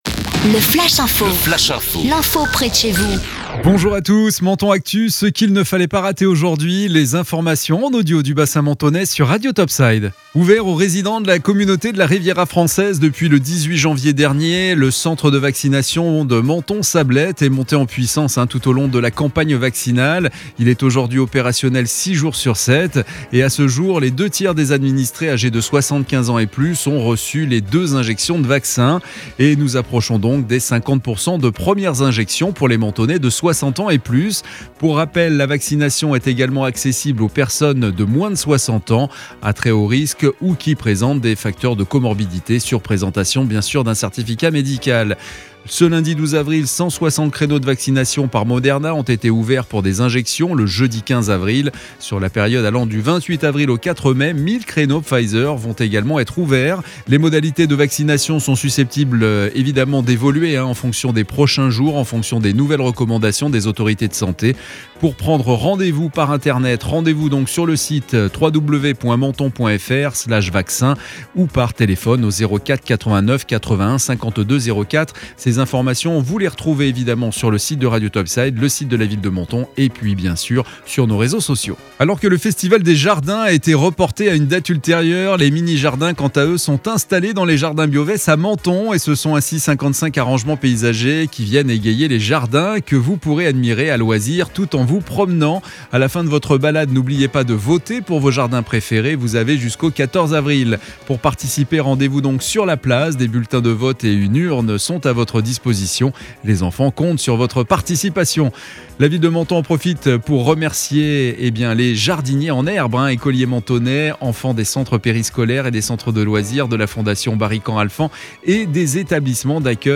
Menton Actu - Le flash info du mardi 13 avril 2021